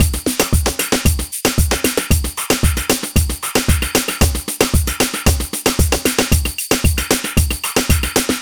Ala Brzl 3 Drmz Dry 1b.wav